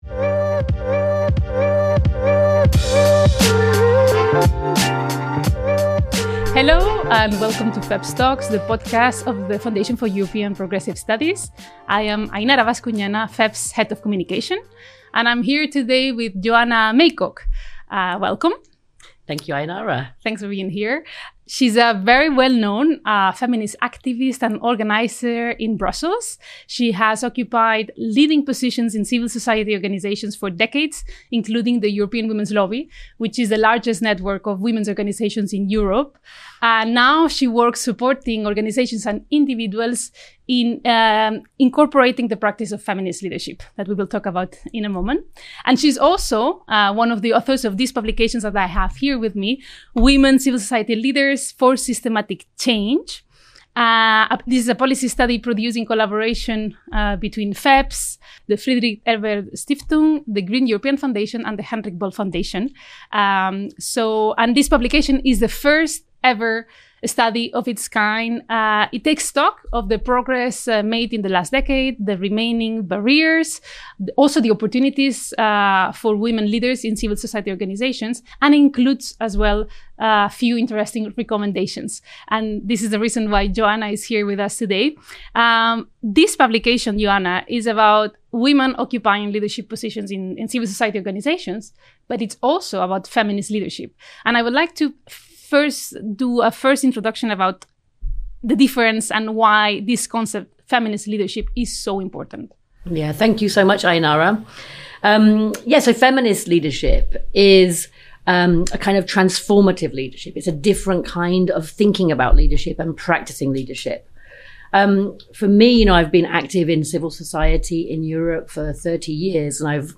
Tune in for a crucial conversation on the fight for feminist digital justice.